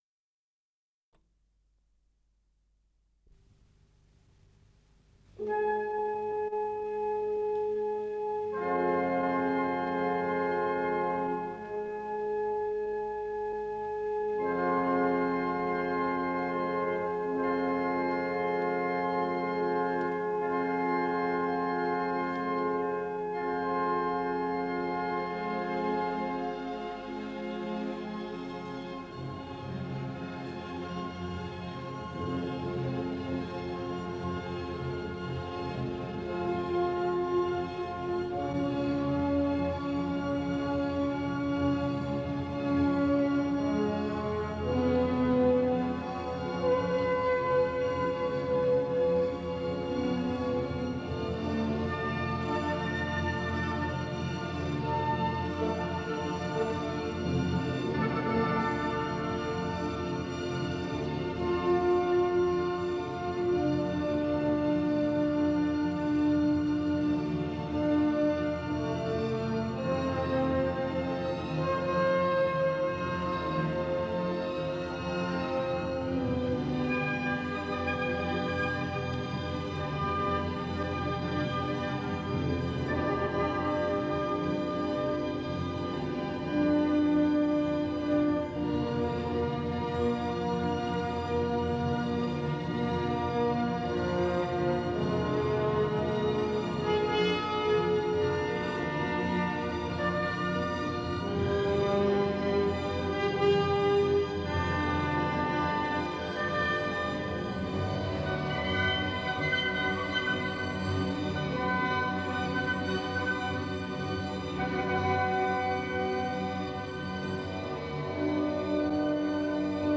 Исполнитель: Государственный симфонический оркестр СССР
Симфоническая фантазия
фа минор